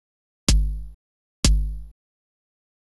KICK010_DISCO_125_X_SC3.wav